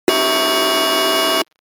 Bad-signal-sound-effect.mp3